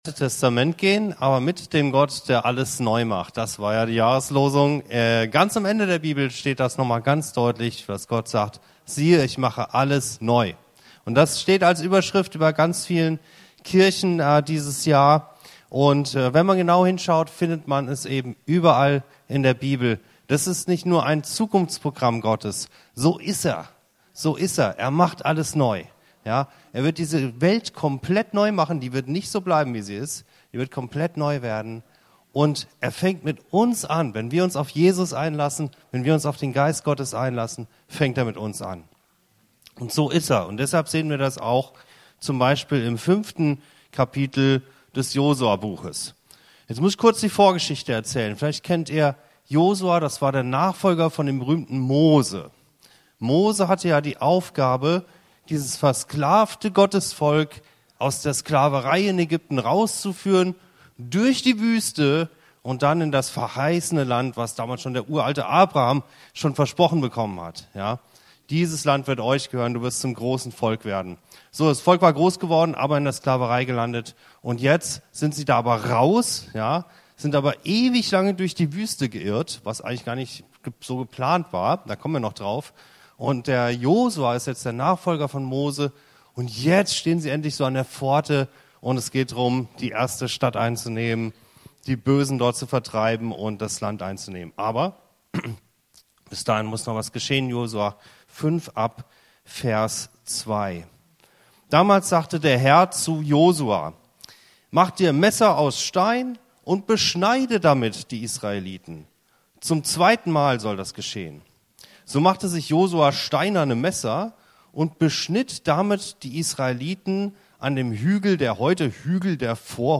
Befreit – und doch noch gebunden? Diese Predigt lädt ein, Ägypten nicht nur zu verlassen, sondern es auch im Herzen zurückzulassen. Ein klarer Cut macht Raum für neue Identität, geistliche Reife und ein Leben aus der Freiheit der Kinder Gottes.